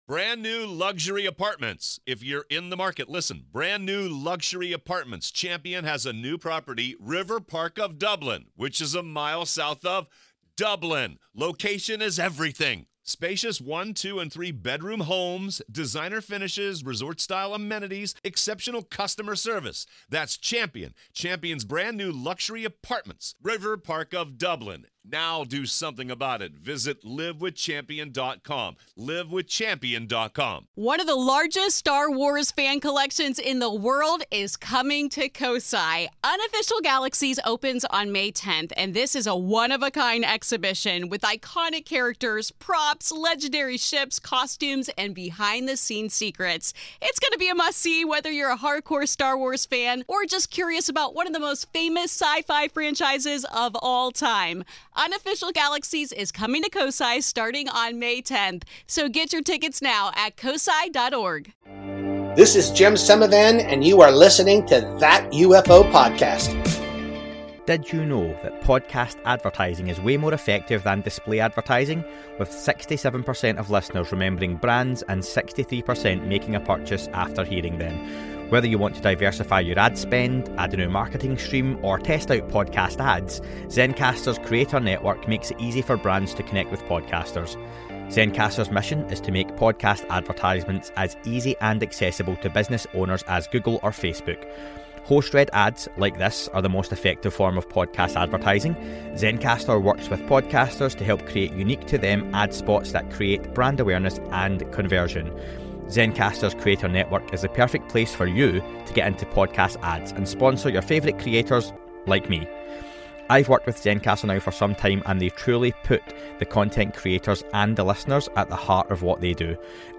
**There are a couple of instances of a few seconds of interference on the recording. It doesn't persist throughout and happens within the first 10 mins. Apologies**